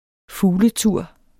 Udtale [ ˈfuːlə- ]